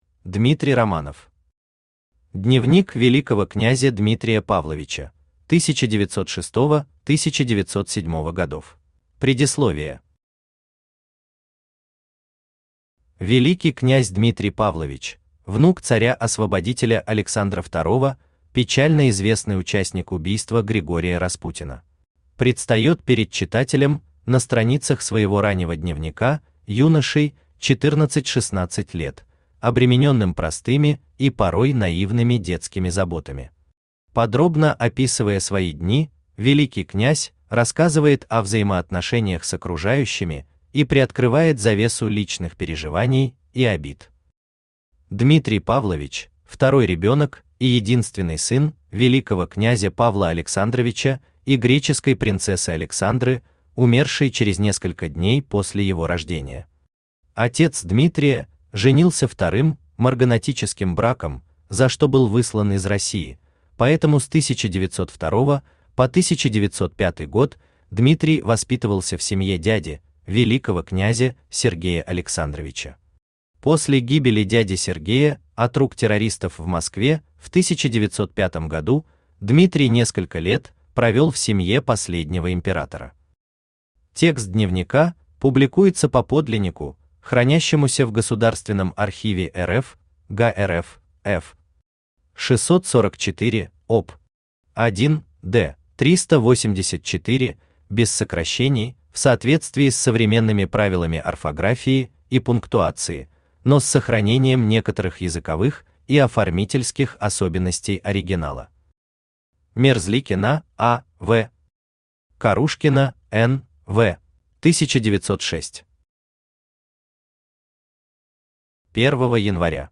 Аудиокнига Дневник великого князя Дмитрия Павловича: 1906-1907 гг.
Автор Дмитрий Павлович Романов Читает аудиокнигу Авточтец ЛитРес.